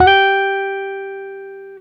Guitar Slid Octave 20-G3.wav